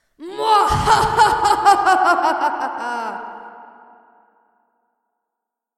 女孩邪恶的笑声 " 邪恶的笑声 3
描述：来自Wayside School的Sideways Stories的录制带来了邪恶的笑声。混响补充道。
Tag: 闲扯 笑声 女孩 女性 邪恶的 女人